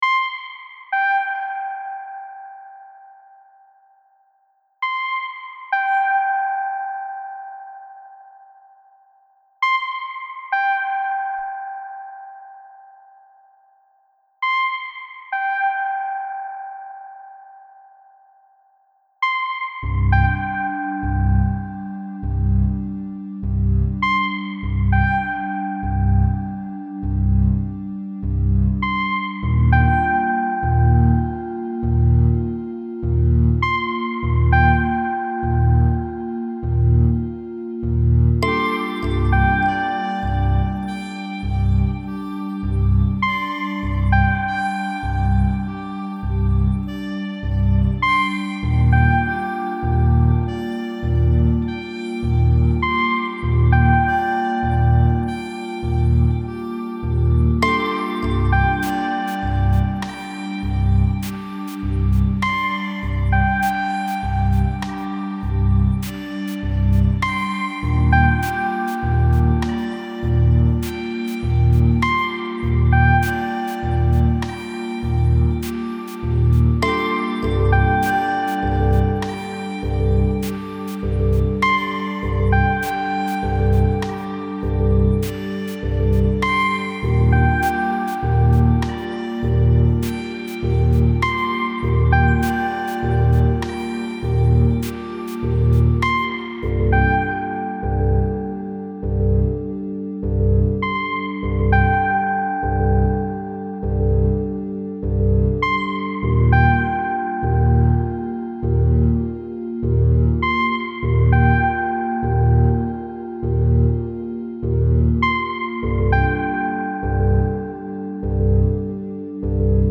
Style Style Ambient, EDM/Electronic
Mood Mood Calming, Relaxed
Featured Featured Drums, Synth
BPM BPM 50